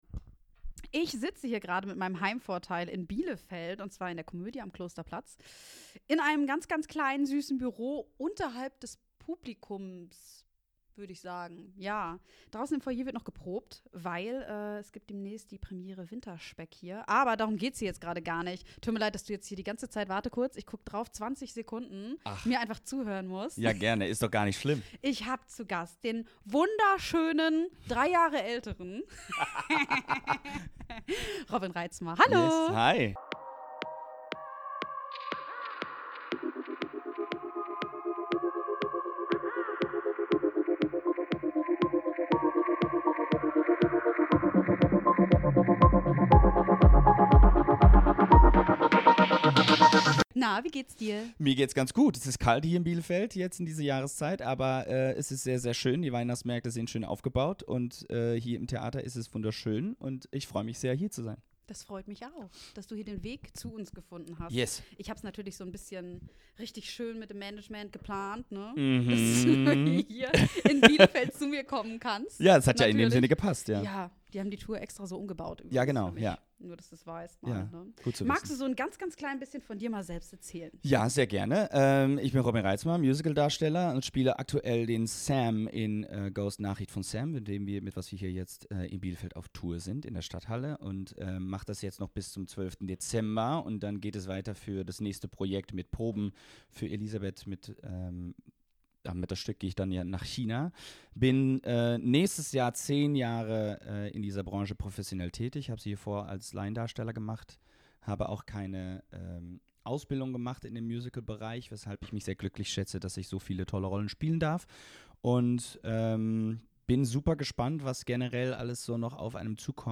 Wir sprechen über Herausforderungen im Berufsalltag, über Rollen, die ihn geprägt haben, und darüber, was ihn künstlerisch antreibt. Ein ehrliches, inspirierendes Gespräch über Leidenschaft, Disziplin und das Leben hinter den Kulissen der Musicalwelt.